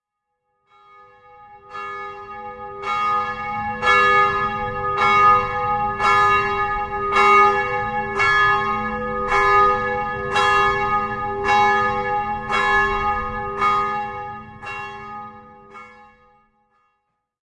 世界各地的氛围 " 2015年Vanzone教堂钟声和花园氛围狗叫声
描述：OKM经典双耳录音，48k 24bit，意大利山区
Tag: 编钟 树皮 Vanzone 教堂 现场 记录 现场录音 振铃 意大利 大气